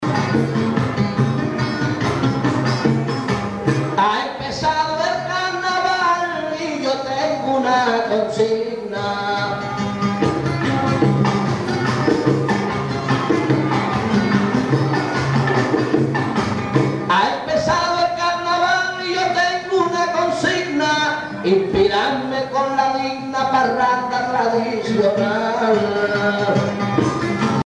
chanté par Guillermo Portabales enregistrée lors du carnaval de juillet 1999
Pièce musicale inédite